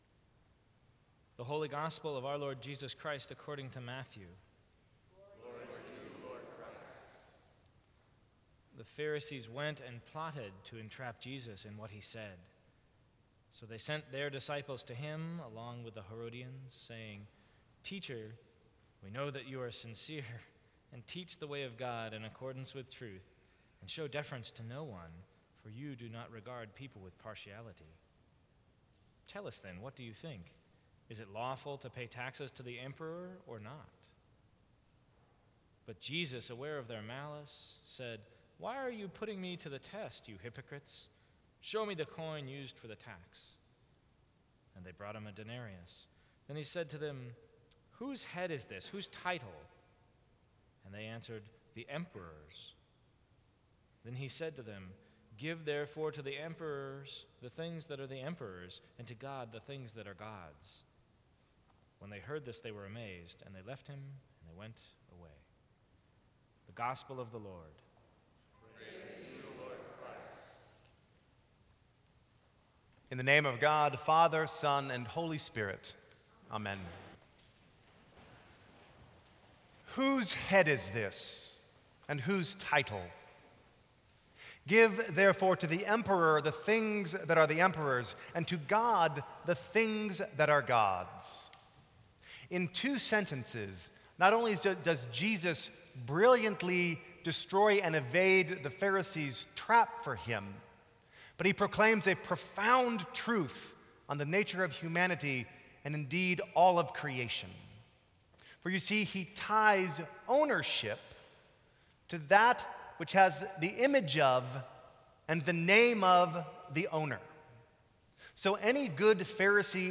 Sermons from St. Cross Episcopal Church October 19, 2014.